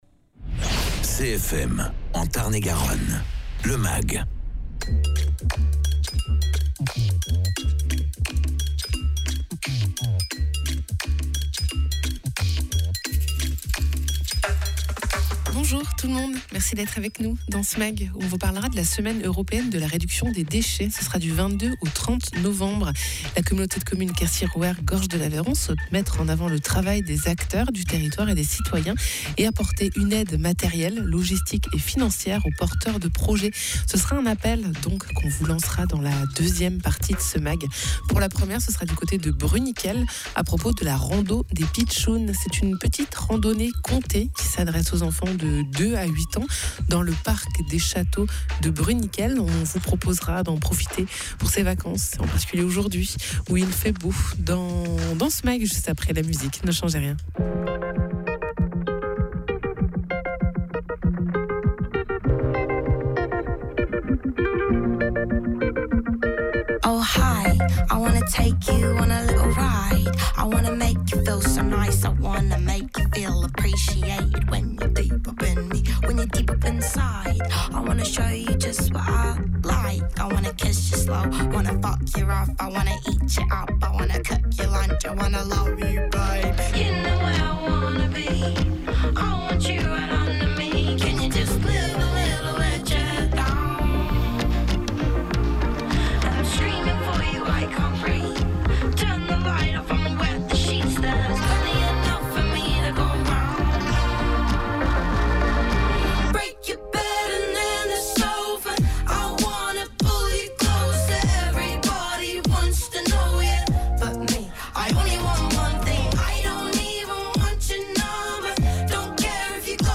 Sébastien Basse, adjoint à la mairie de Bruniquel